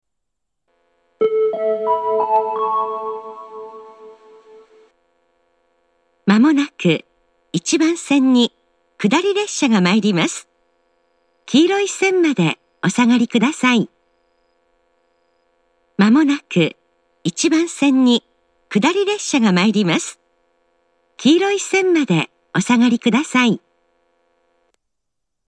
自動放送は仙石型で、接近放送では番線に加えて上り/下りを案内します。
日立・いわき方面   仙石型（女性）
接近放送